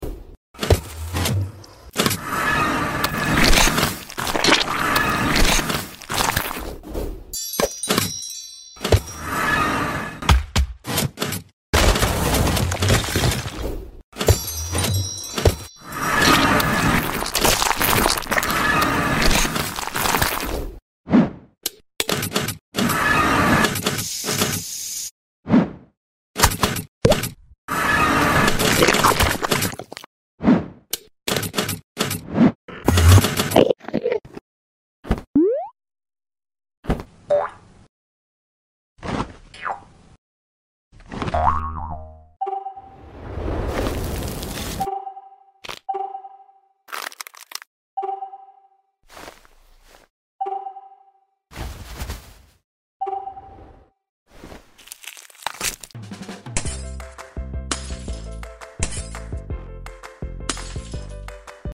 Minecraft Vs Real Life In Sound Effects Free Download